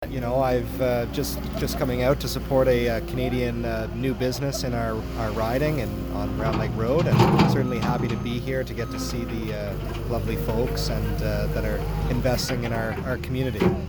Newly elected MPP Billy Denault was on hand for the grand opening and had this to say about the celebration: